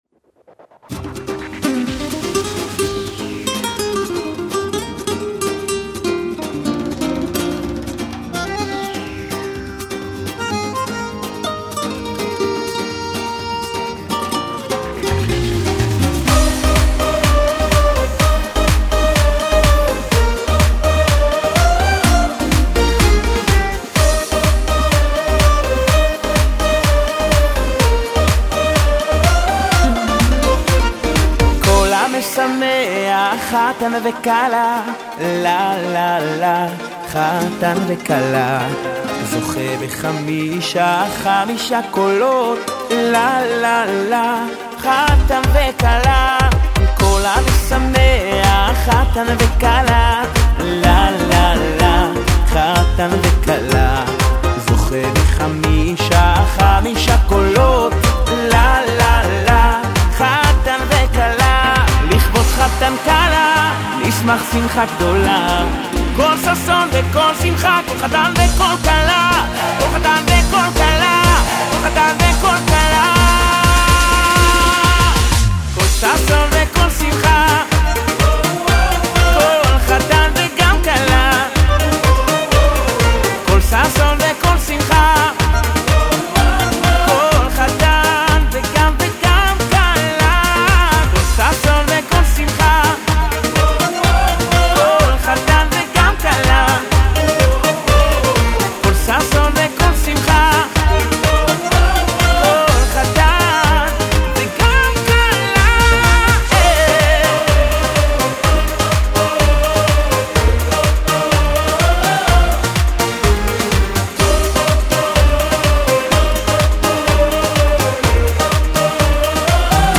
שיר בסגנון עכשווי